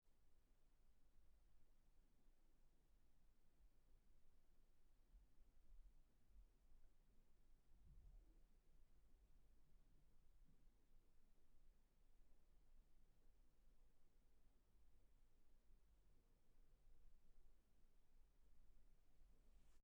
Female
Approachable, Bright, Character, Confident, Conversational, Engaging, Friendly, Natural, Smooth, Upbeat, Versatile, Warm, Witty, Young
English and German with Russian accent
Microphone: Audio Technica 4033